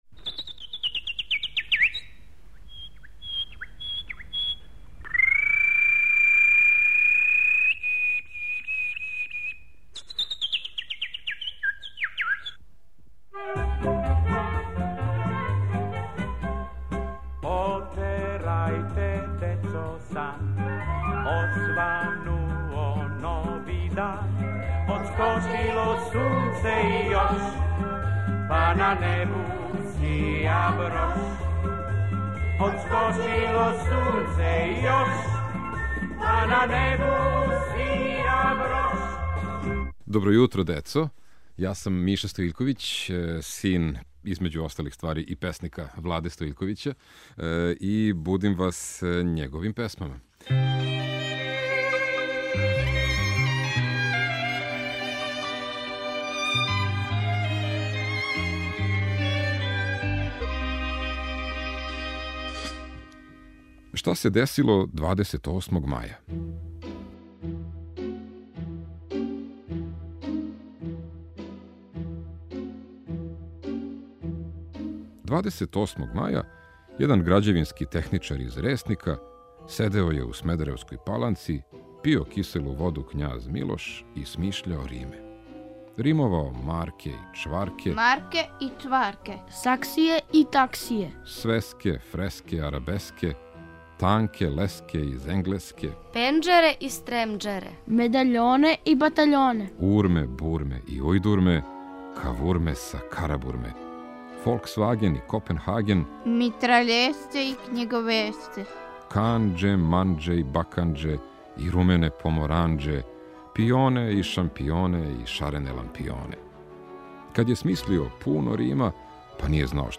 Поезија, Влада Стоиљковић